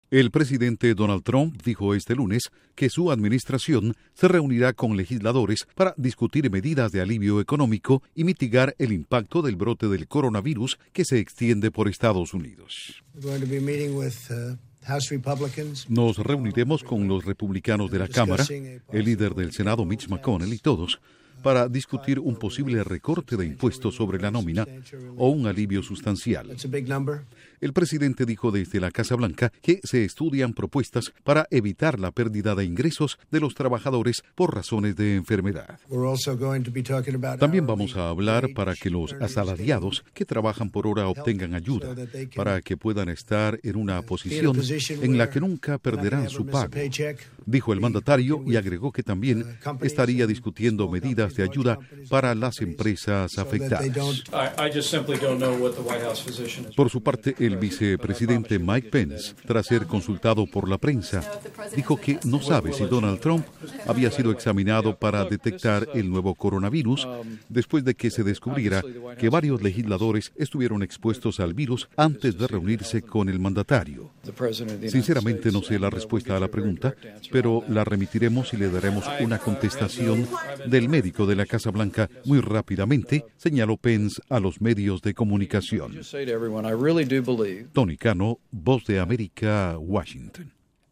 Duración: 1:30 Con declaraciones de Trump y Pence